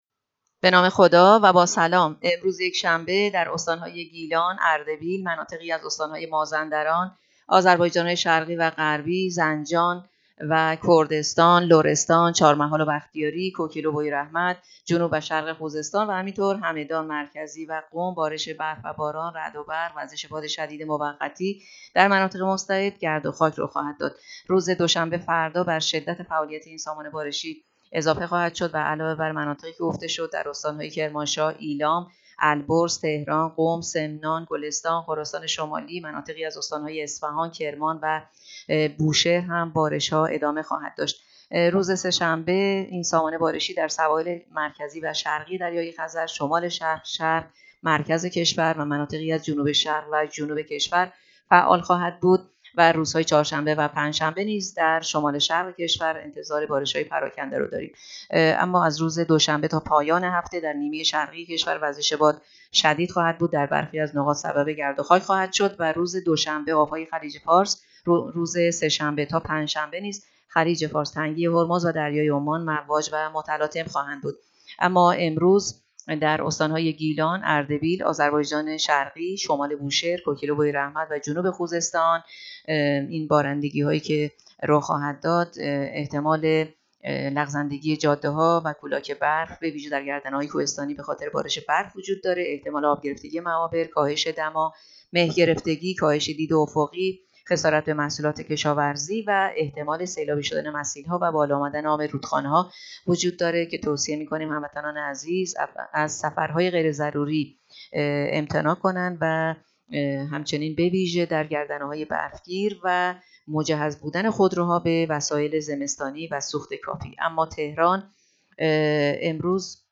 گزارش رادیو اینترنتی پایگاه‌ خبری از آخرین وضعیت آب‌وهوای۷ بهمن؛